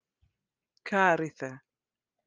Jordanian